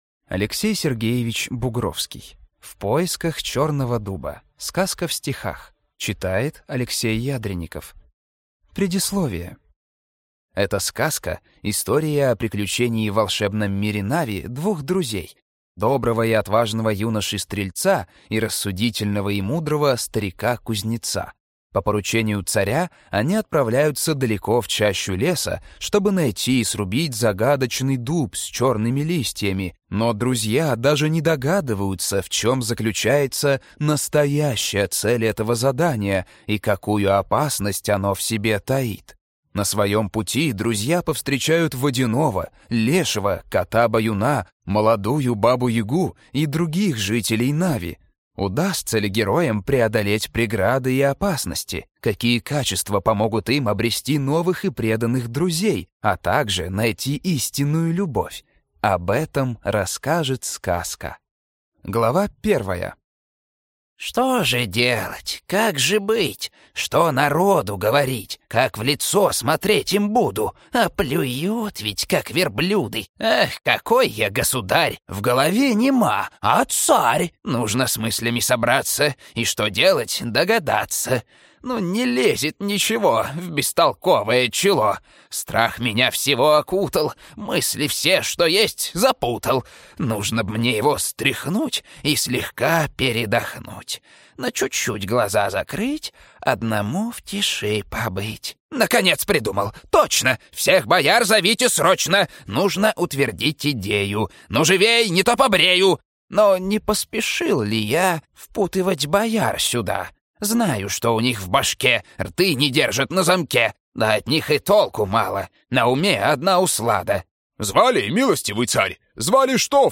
Аудиокнига В поисках чёрного дуба | Библиотека аудиокниг
Прослушать и бесплатно скачать фрагмент аудиокниги